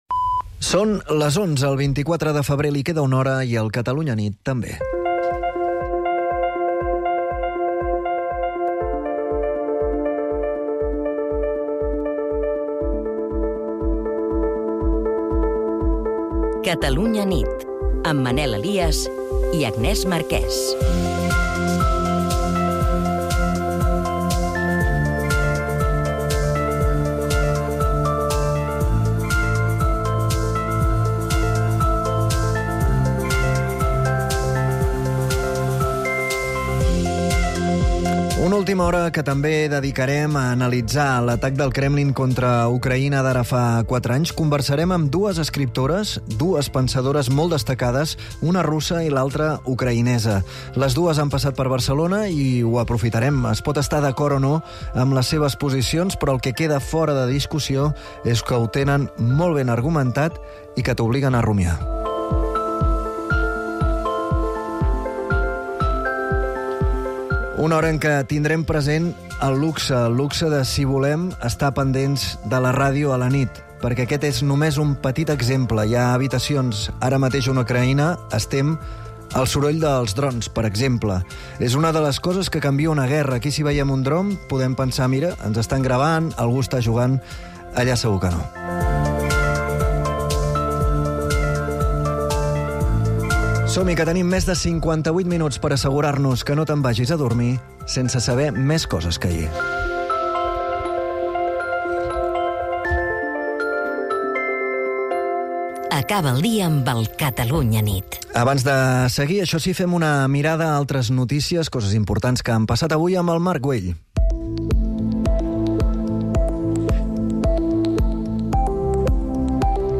El compromís d'explicar tot el que passa i, sobretot, per què passa és la principal divisa del "Catalunya nit", l'informatiu nocturn de Catalunya Ràdio, dirigit per Manel Alías i Agnès Marquès.